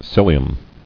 [cil·i·um]